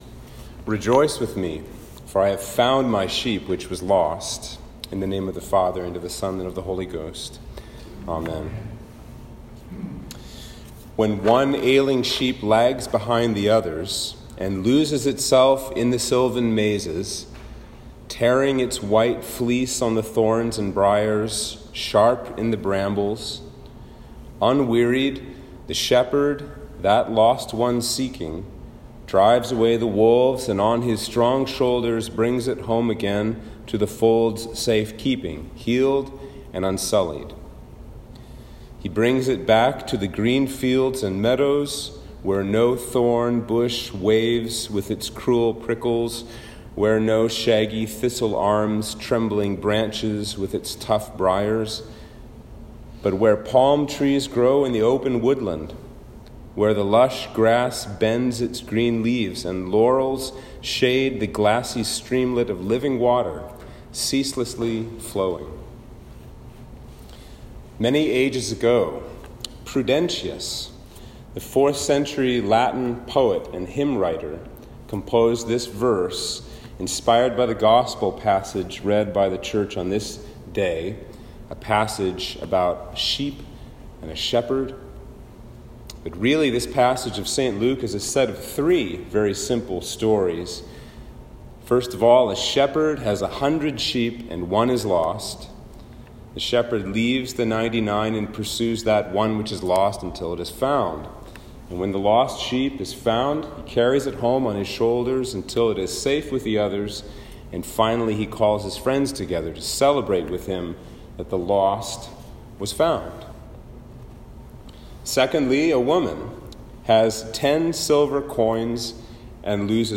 Sermon for Trinity 3
Sermon-for-Trinity-3-2021.m4a